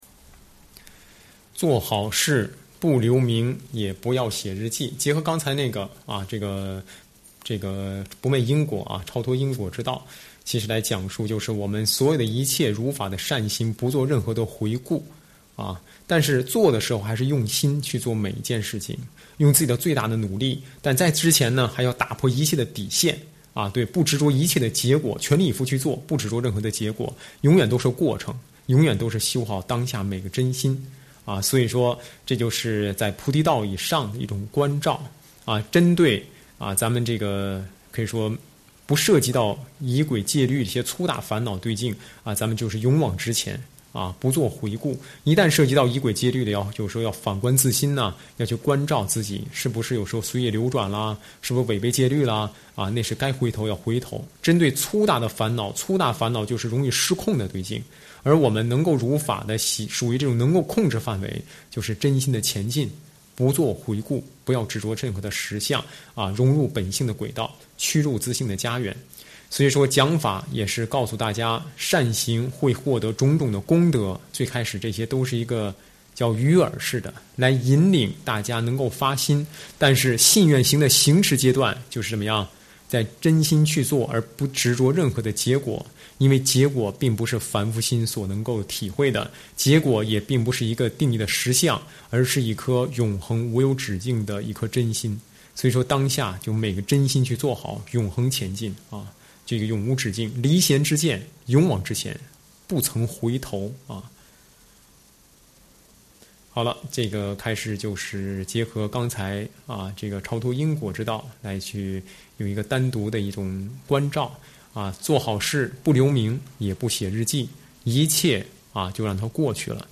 随笔开示
上师语音开示